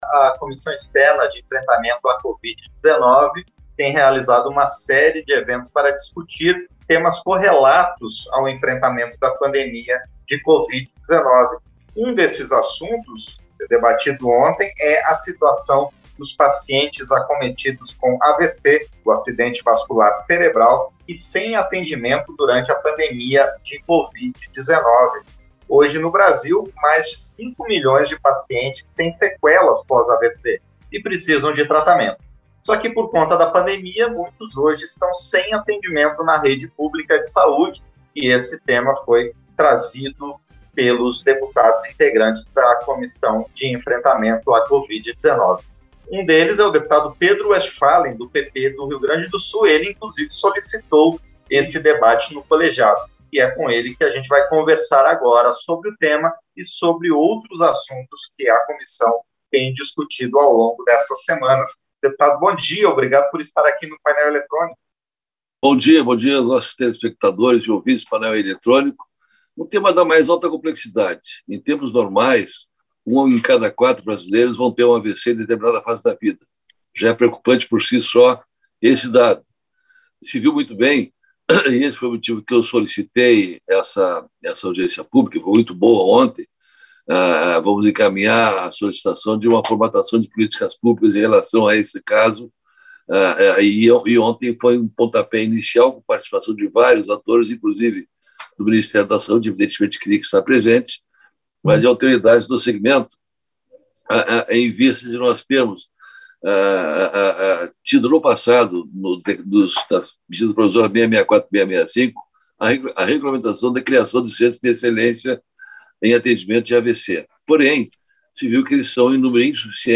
Entrevista - Dep. Pedro Westphalen (PP-RS)